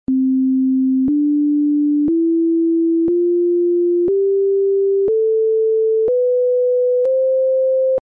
Nous avons (peut-être...) l’impression d’entendre une gamme " normale "